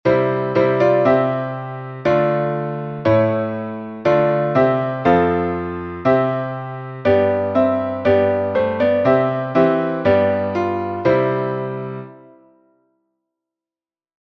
Киевский распев, глас 1